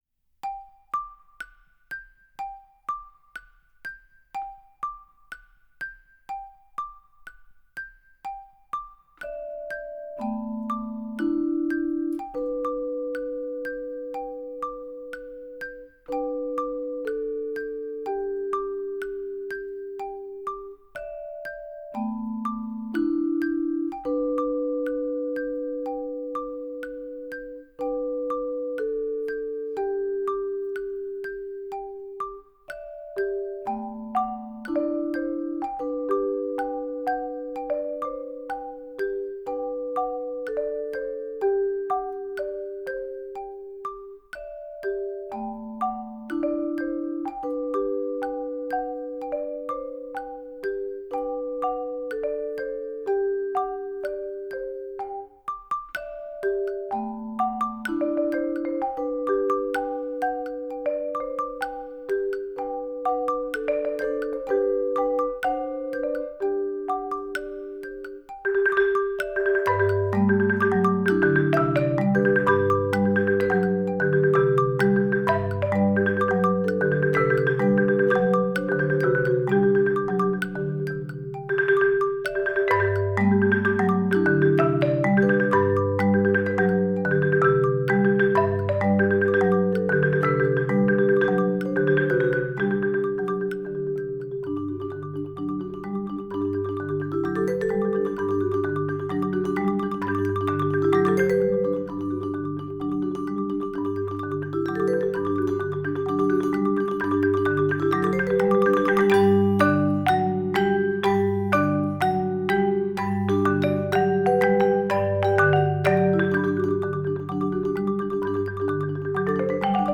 Voicing: Mallet Quartet